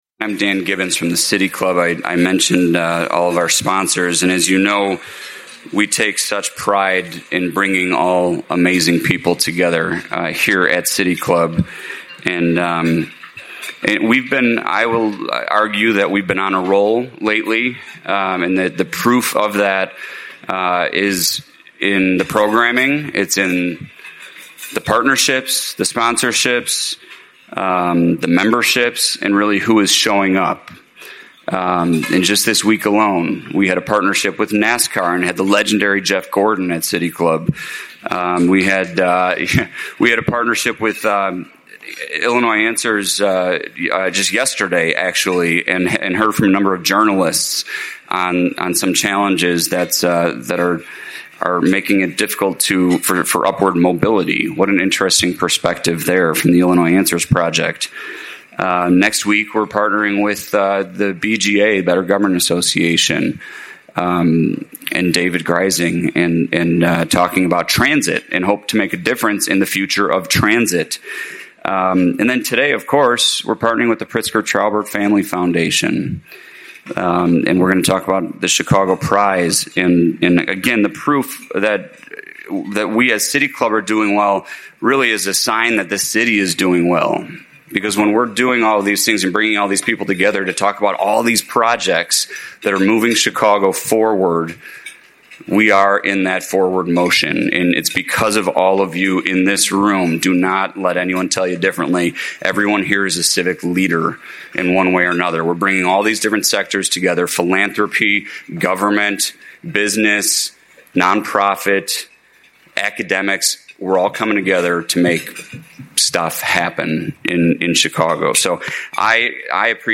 Hear how four community leaders plan to use the $10 million Chicago Prize to advance economic opportunity in their neighborhoods: Bronzeville, North Lawndale, Back of the Yards, Chicago Lawn, Roseland and Pullman. The Cottage Grove Corridor Collective aims to bring commercial activity to the growing Bronzeville neighborhood. Reclaiming Chicago wants to build 2,000 homes across four neighborhoods.